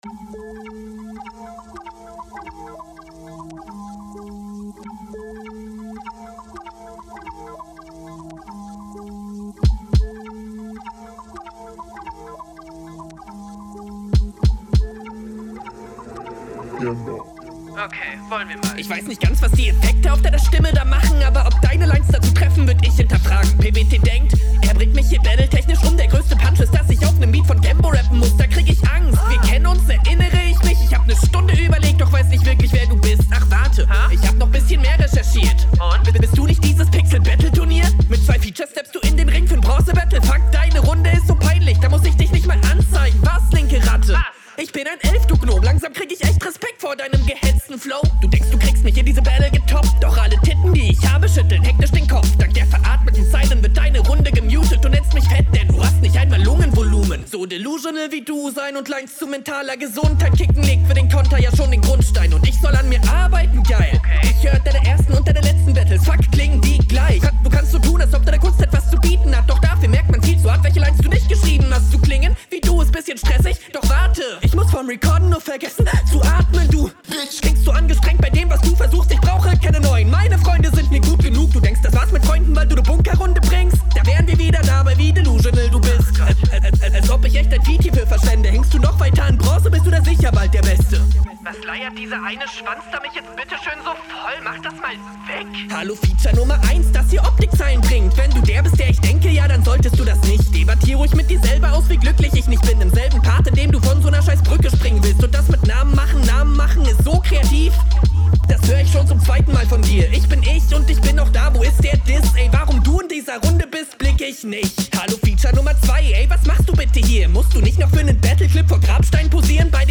Textlich und Flowlich super.